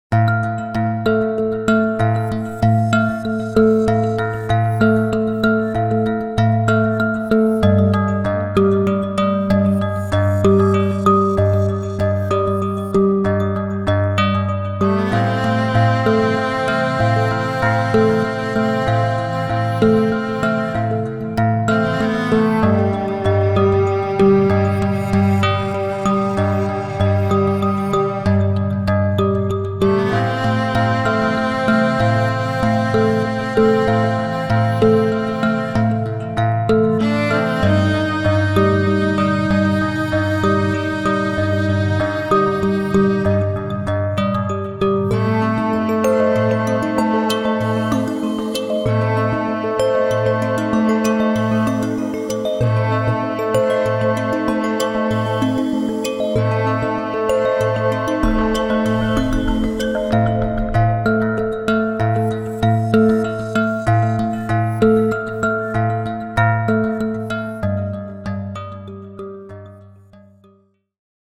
フリーBGM イベントシーン ホラー・不気味・不穏
フェードアウト版のmp3を、こちらのページにて無料で配布しています。